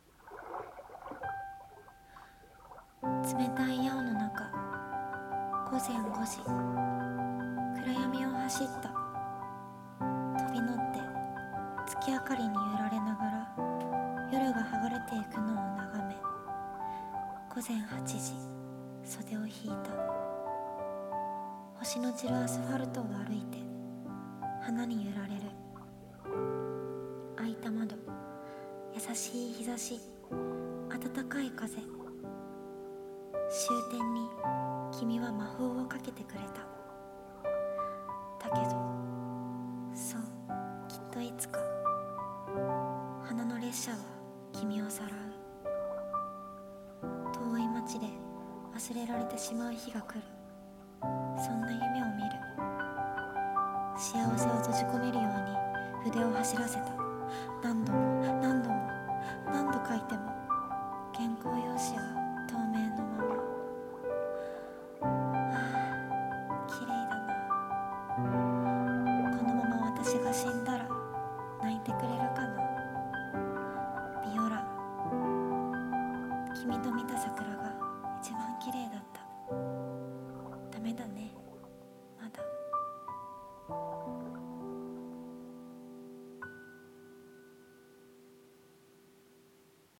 朗読「 花の列車